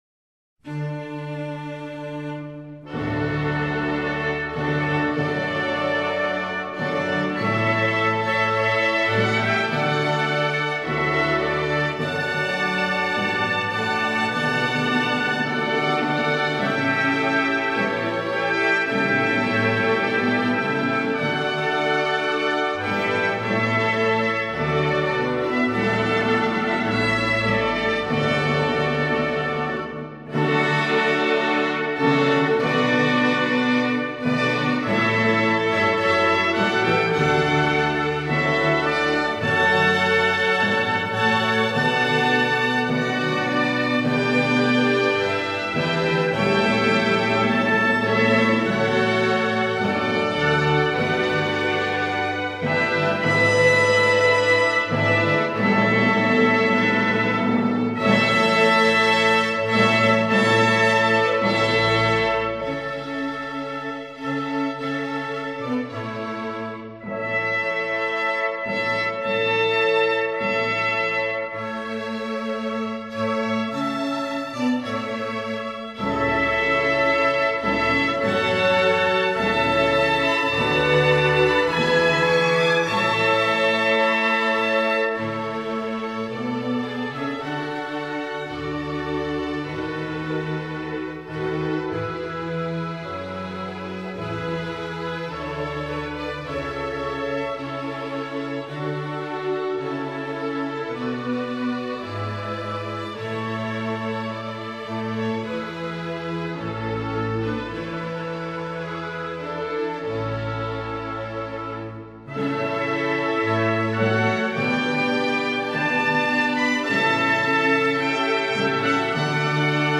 Jų muzika, kaip ir daugelio Hendelio kūrinių, didinga, įspūdinga.